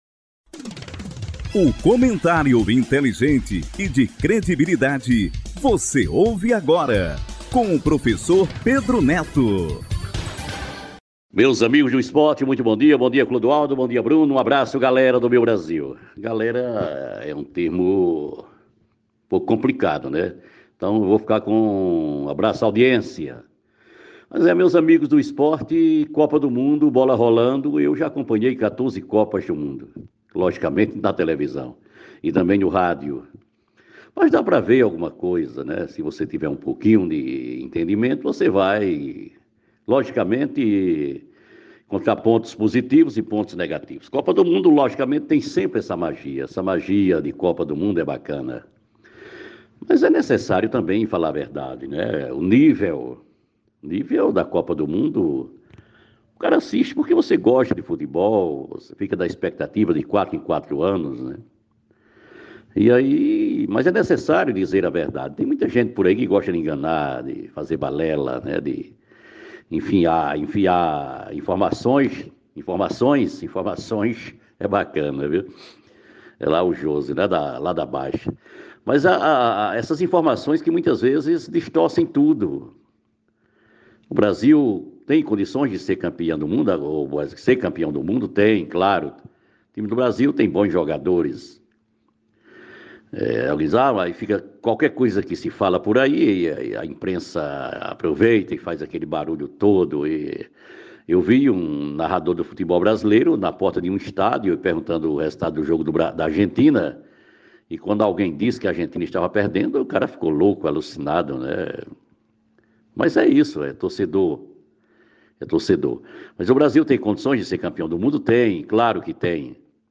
comentário